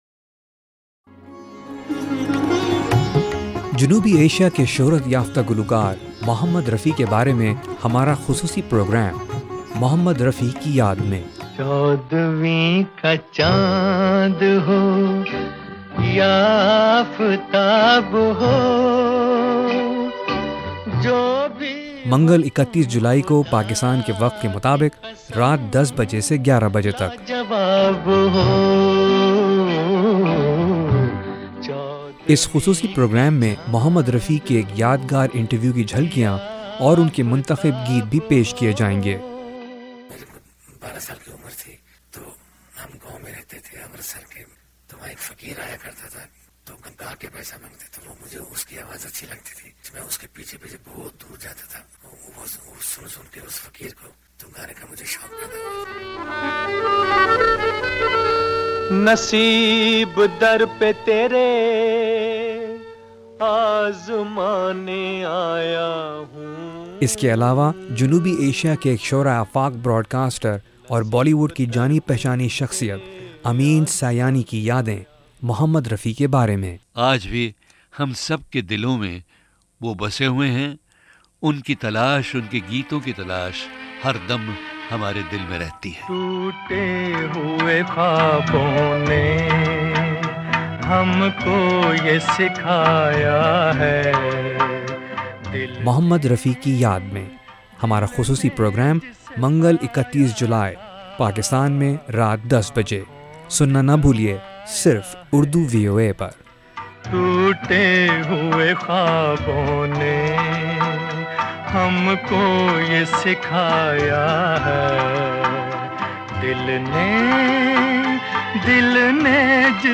وائس آف امریکہ اردو سروس کا خصوصی پروگرام
اس پروگرام میں آپ محمد رفیع کے ایک خصوصی انٹرویو کی جھلکیاں اور انہی کے پسند کے نغموں کا انتخاب سن سکیں گے۔